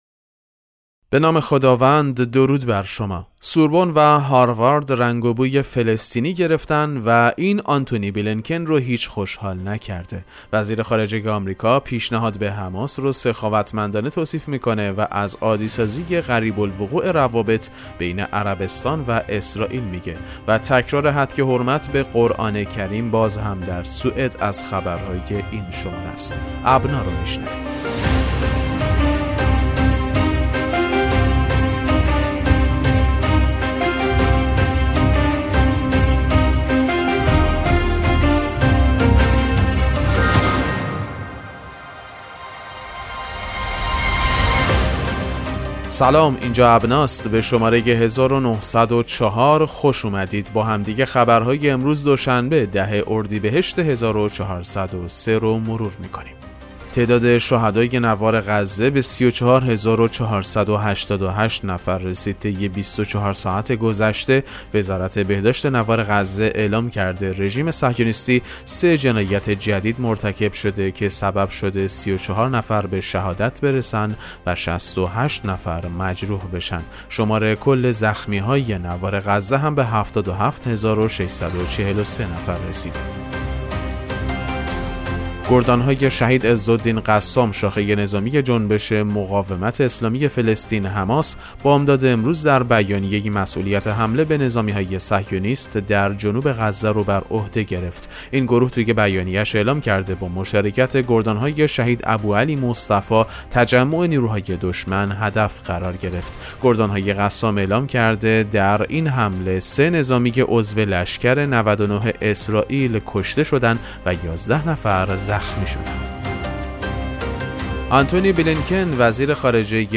پادکست مهم‌ترین اخبار ابنا فارسی ــ 10 اردیبهشت 1403